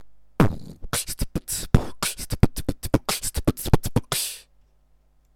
Форум российского битбокс портала » Реорганизация форума - РЕСТАВРАЦИЯ » Выкладываем видео / аудио с битбоксом » Мои биты (Сюда быду выкладывать все известные мне биты)
в микрофоне звук конечно искажается, поэтому кач-во неочень